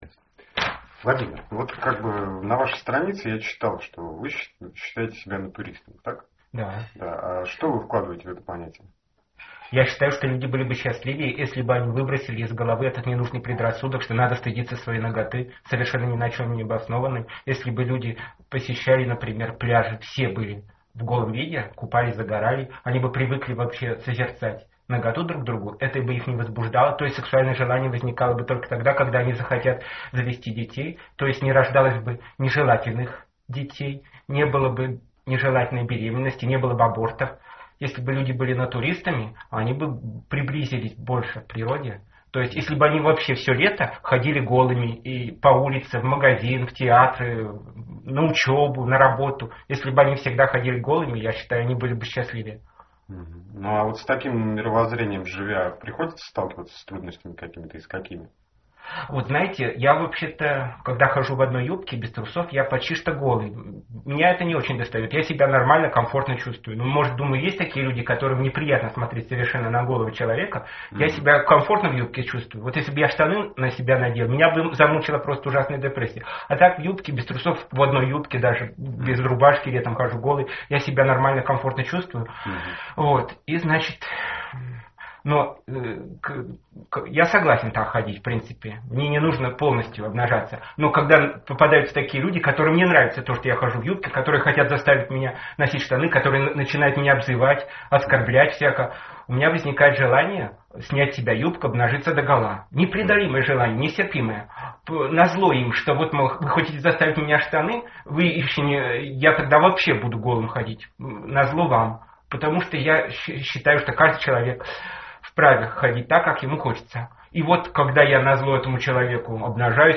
Телепередача «Экстренный вызов» с моим участием, вышедшая в эфир 18 марта 2011 года в 12:00, не осветила моих взглядов, которые я давал в Интервью, записанном на диктофон.